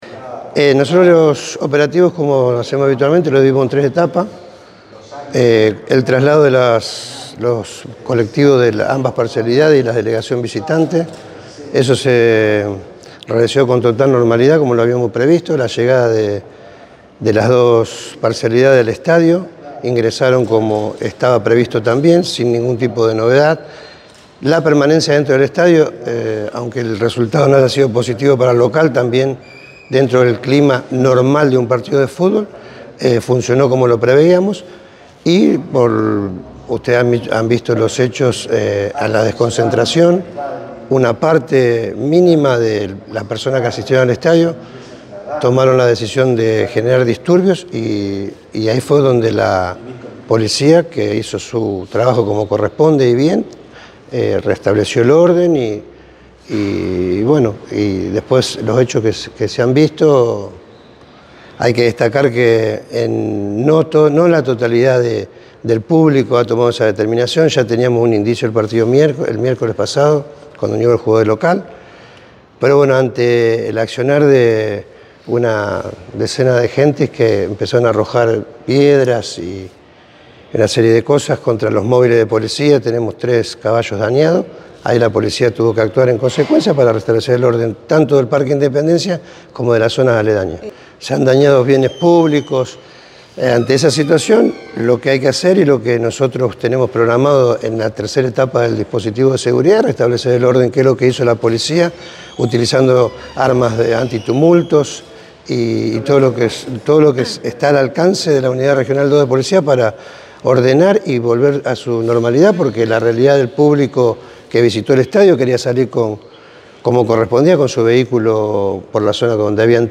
Declaraciones de Velázquez